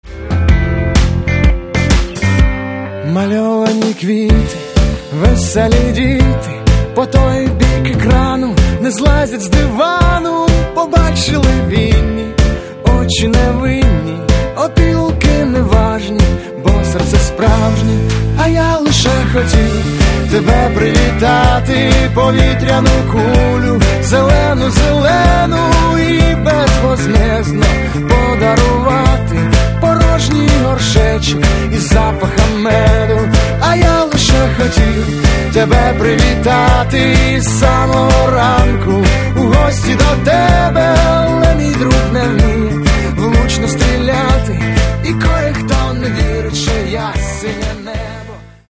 Каталог -> Рок и альтернатива -> Поэтический рок